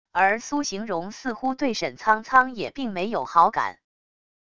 而苏行容似乎对沈苍苍也并没有好感wav音频生成系统WAV Audio Player